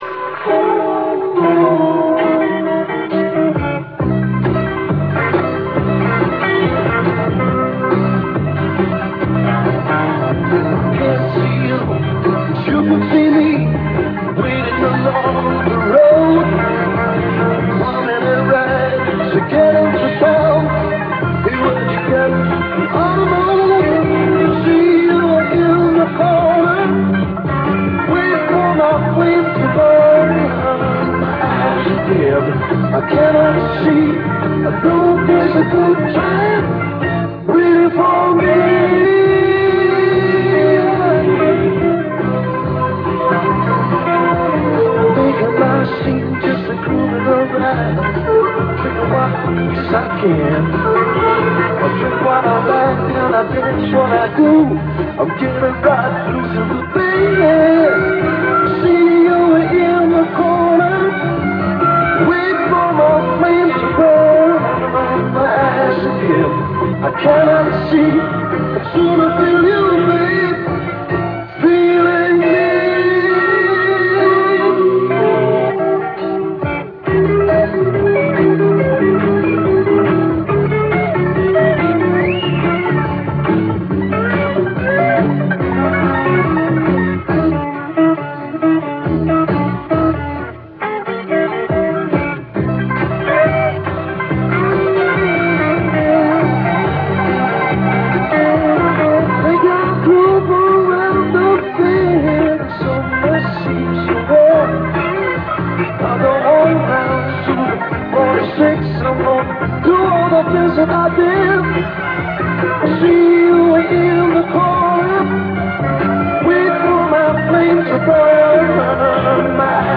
Bootleg Live Version!